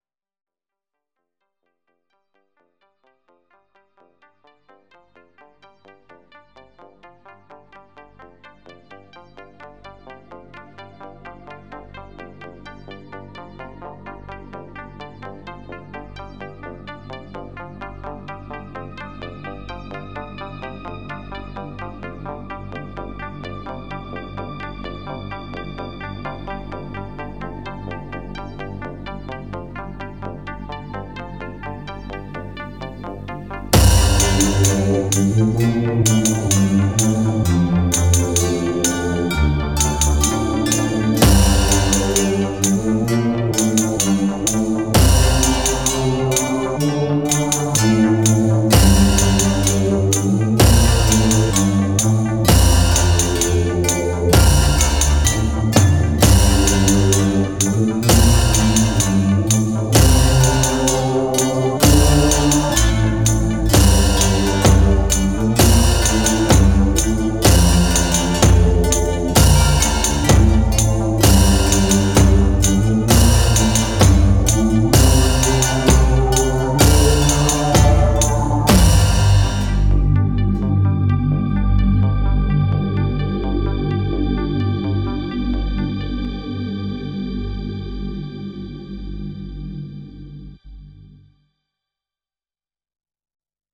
Originally made on the -1 with sounds designed on and some OP-1 presets. Mixed in GarageBand.
I called it Chant because it reminded me of a procession of self-flagellating monks.